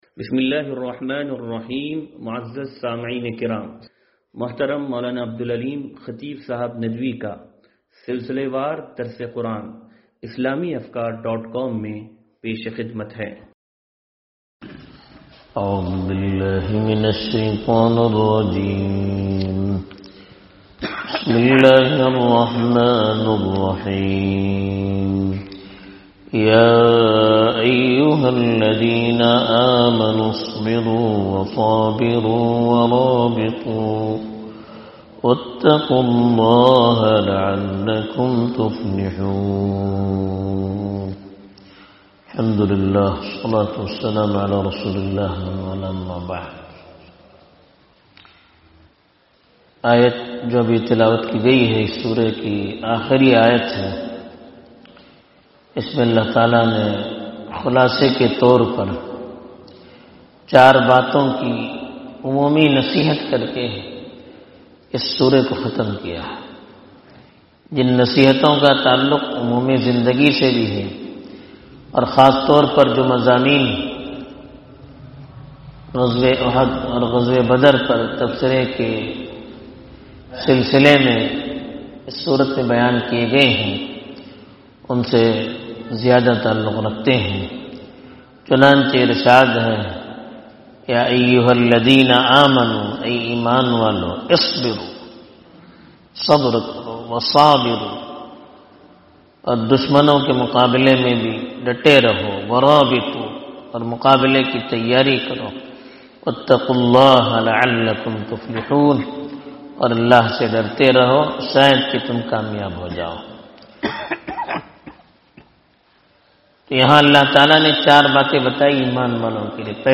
درس قرآن نمبر 0319